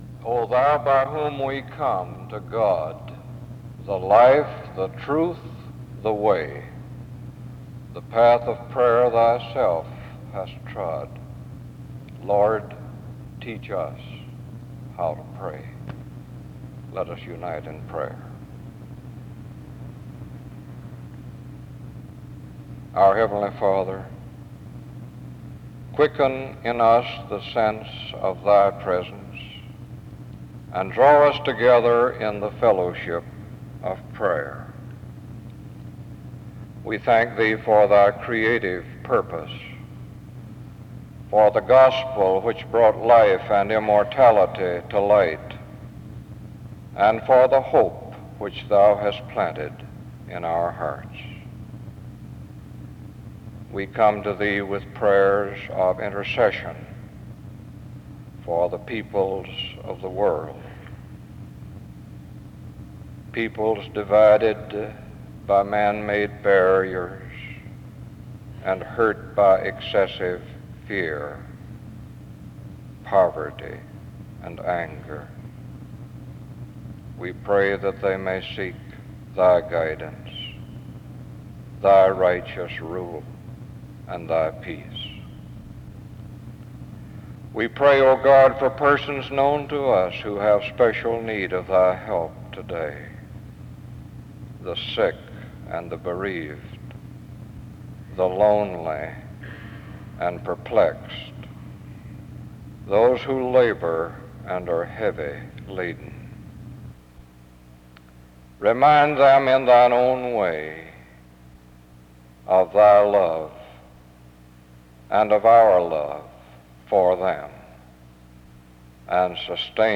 Music plays from 2:26-5:33.
In Collection: SEBTS Chapel and Special Event Recordings SEBTS Chapel and Special Event Recordings